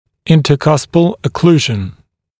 [ˌɪntə’kʌspl ə’kluːʒn][ˌинтэ’каспл э’клу:жн]межбугорковая окклюзия